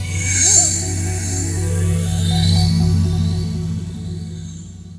CHORD48 02-R.wav